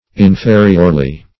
inferiorly - definition of inferiorly - synonyms, pronunciation, spelling from Free Dictionary Search Result for " inferiorly" : The Collaborative International Dictionary of English v.0.48: Inferiorly \In*fe"ri*or*ly\, adv.
inferiorly.mp3